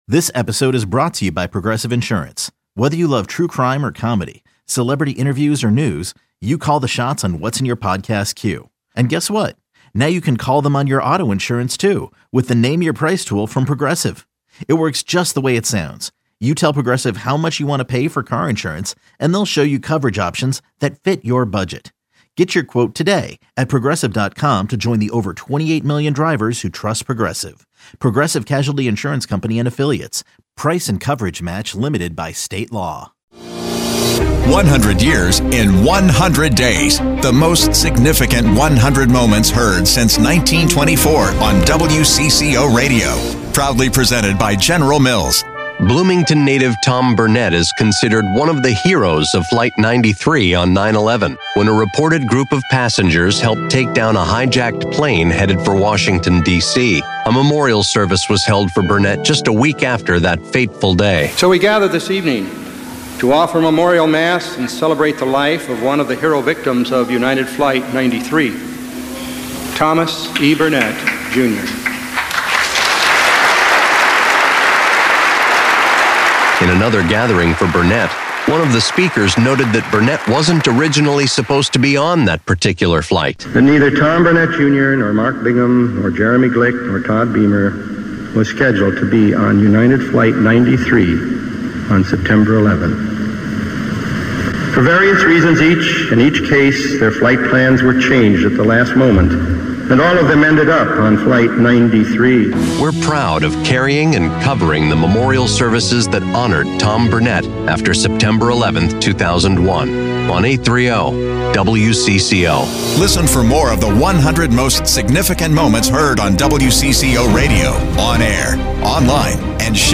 Bloomington native Tom Burnett is considered one of the heroes of flight 93 on 9/11, when passengers helped take down a hijacked plane heading for Washington. WCCO covered his memorial, another memorable moment in our 100 years.